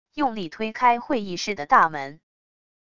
用力推开会议室的大门wav音频